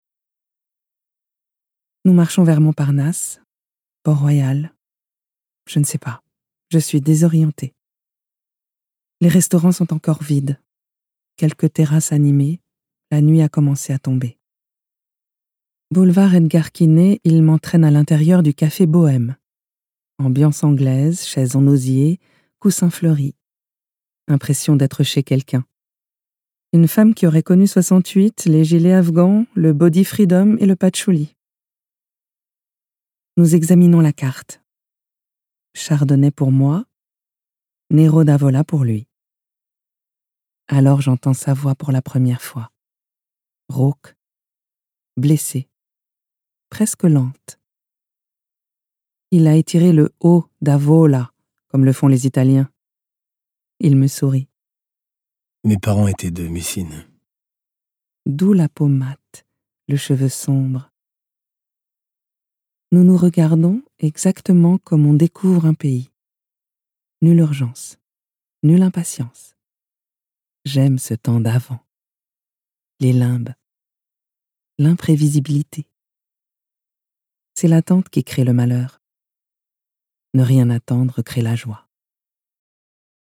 Voix off
10 - 80 ans - Mezzo-soprano